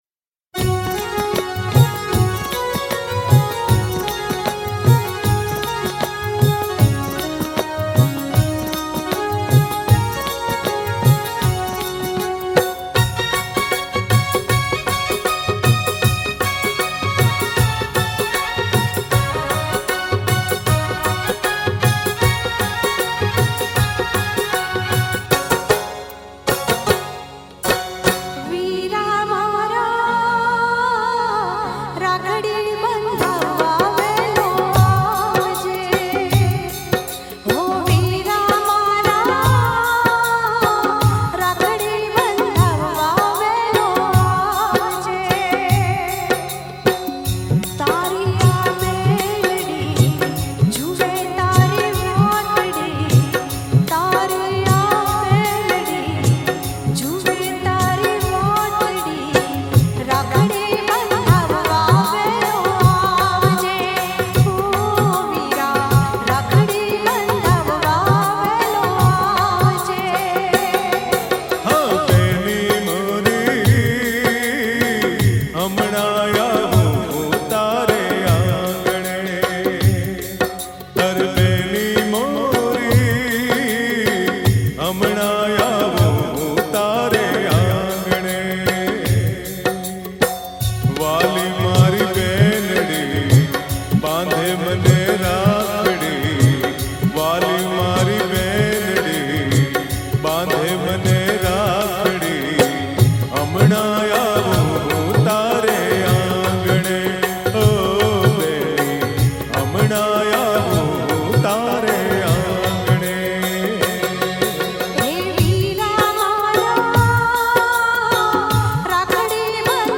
Gujarati Songs Raksha Bandhan Special Song